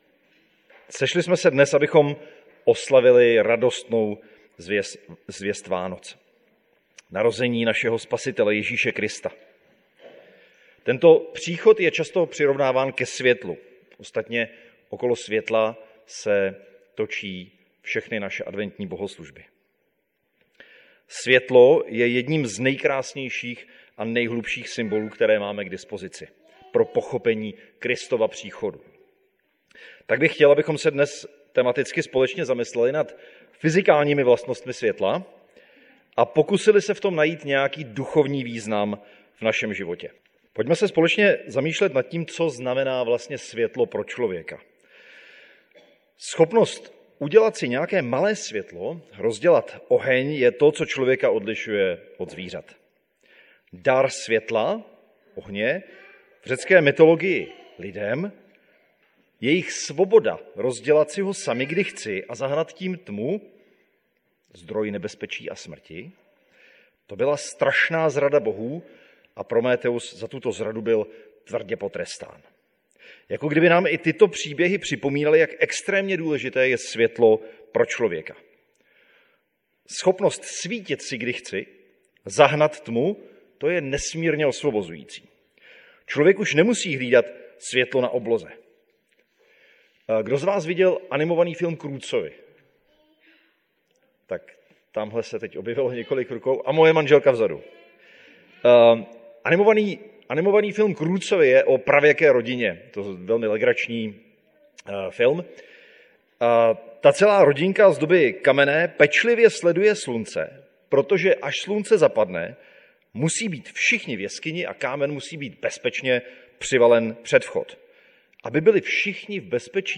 Nedělní bohoslužby Husinec